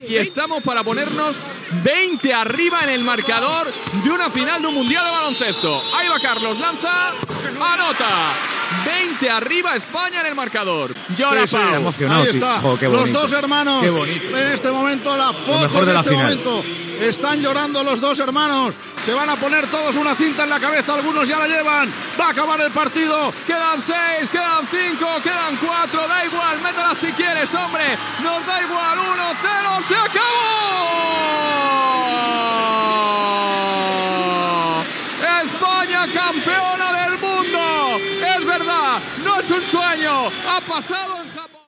Narració d'un llançament triple d'Espanya i temps mort de Grècia.
Esportiu